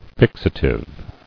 [fix·a·tive]